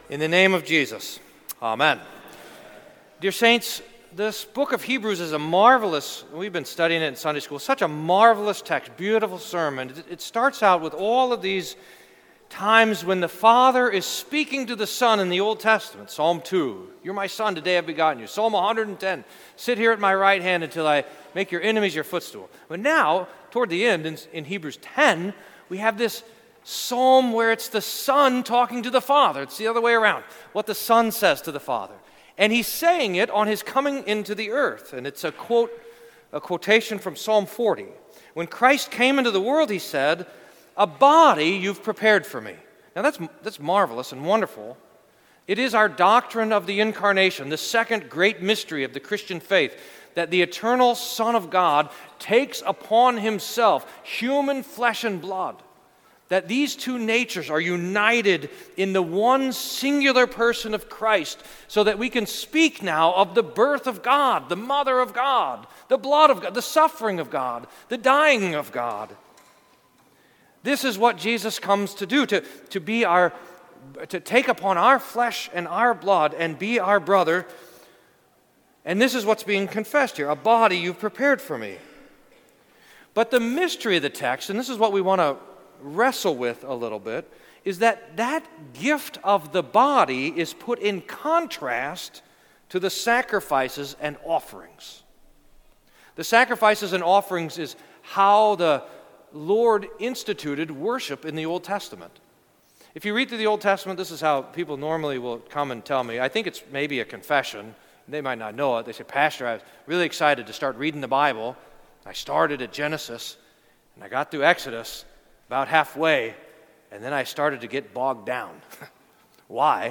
Sermon for Fourth Sunday in Advent